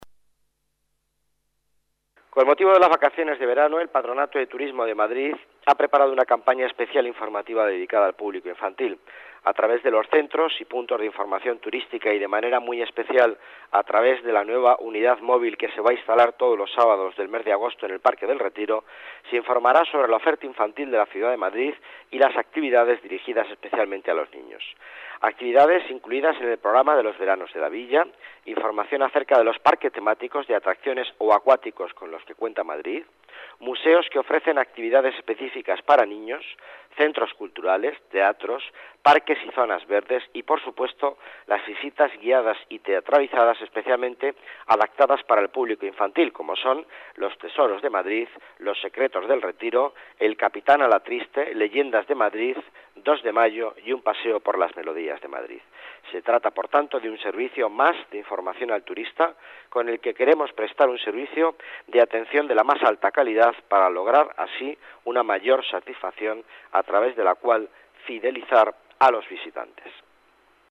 Nueva ventana:Declaraciones de Miguel Ángel Villanueva, delegado de Economía y Empleo